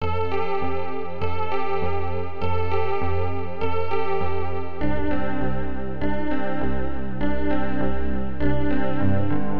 块状正弦波
标签： 100 bpm Chill Out Loops Synth Loops 1.62 MB wav Key : B
声道立体声